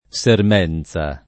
[ S erm $ n Z a ]